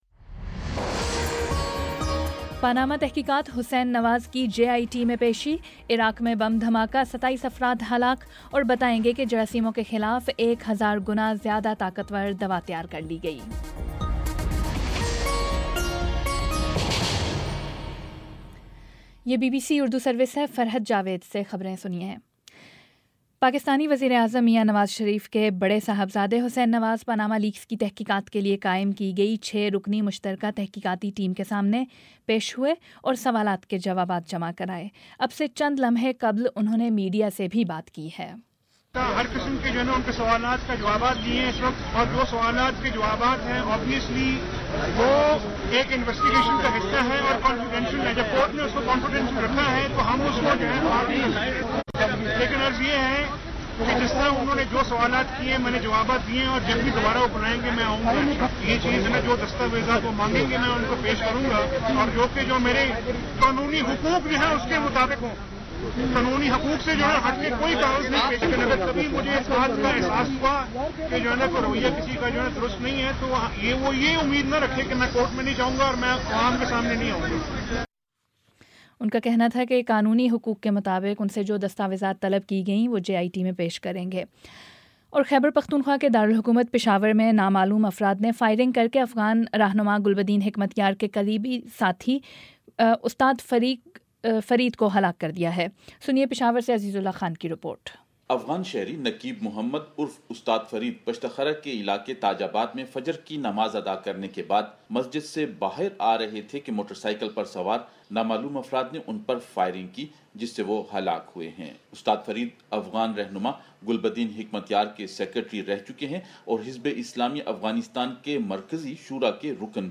مئی 30 : شام چھ بجے کا نیوز بُلیٹن